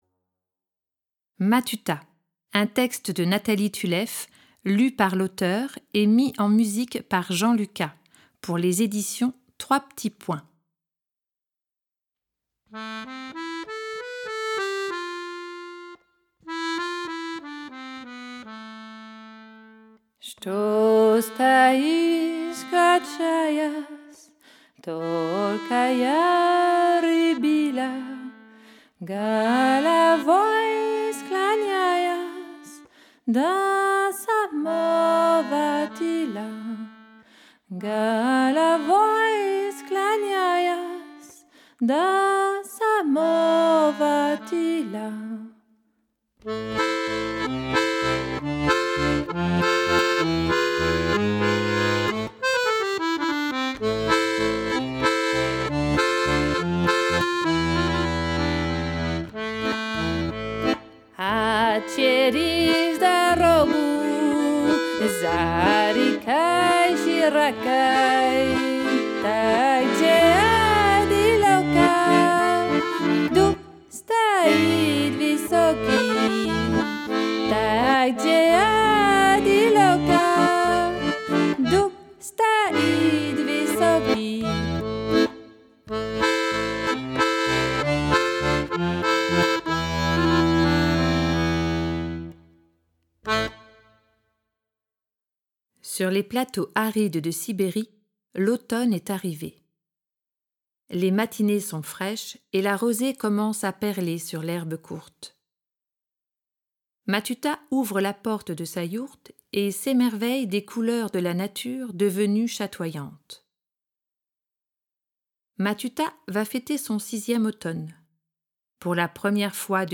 Diffusion distribution ebook et livre audio - Catalogue livres numériques
Matuta va alors entreprendre, au son des accordéon, guimbarde, conque, trombone, sifflet à nez ou encore bombo, un voyage à la rencontre des autres et de ses propres trésors cachés.